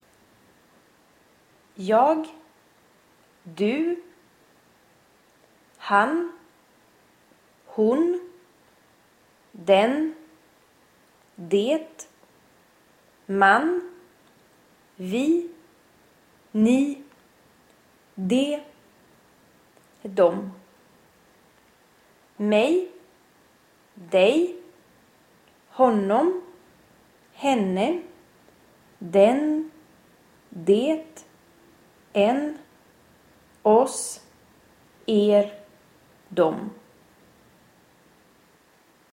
شما عزیزان می توانید به تلفظ صحیح این کلمات از طریق فایل صوتی زیر نیز توجه نمایید:
Swedish-Subject-and-Object-Pronouns.mp3